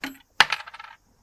关于冰块放入音效的PPT演示合集_风云办公